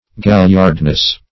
Galliardness \Gal"liard*ness\